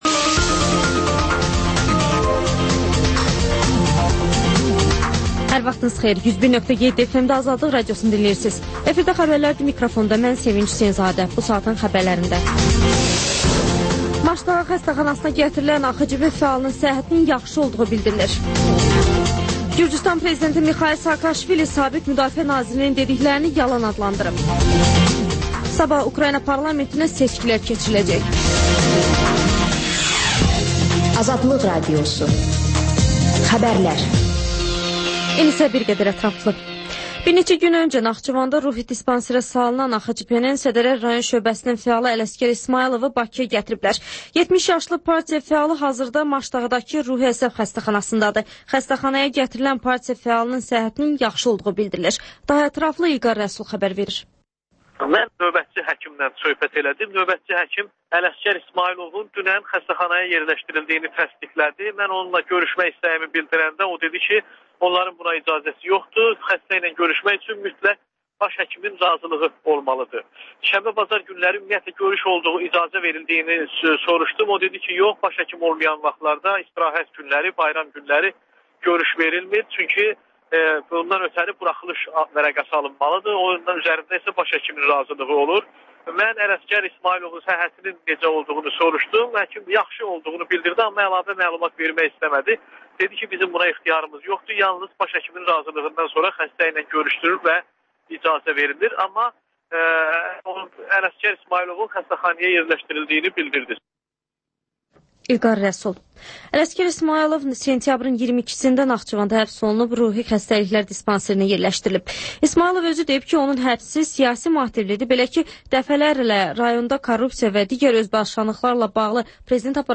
Xəbərlər, ardınca PANORAMA verilişi: Həftənin aktual mövzusunun müzakirəsi.